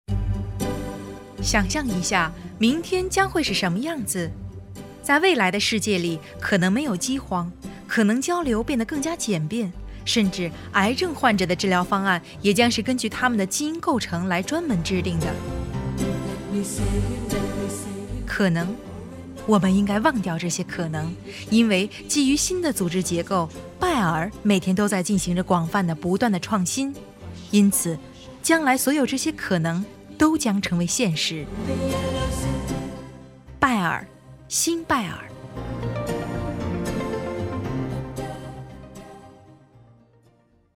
Sprecherin chinesisch, Muttersprachlerin.
Sprechprobe: Industrie (Muttersprache):
Chinese female voice-over talent